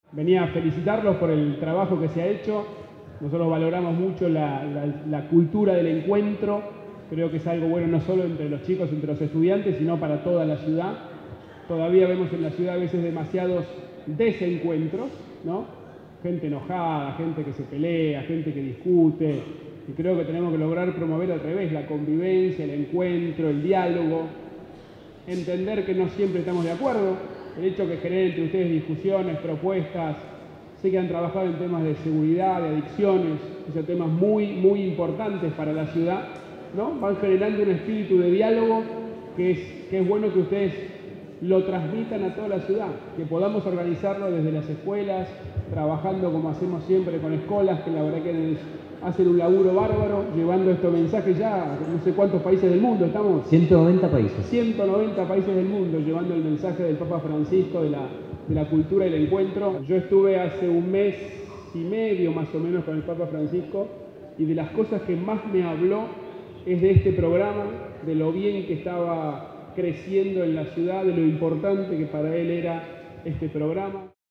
“Vine a felicitarlos por el trabajo que se ha hecho”, sostuvo en el acto realizado en la Escuela Técnica Nº 9 "Ingeniero Huergo".
“Vine a felicitarlos por el trabajo que se ha hecho”, sostuvo Rodríguez Larreta al dirigirse a los alumnos, y resaltó que su administración “valora mucho la cultura del encuentro, que es algo bueno no sólo entre los estudiantes sino para toda la Ciudad”.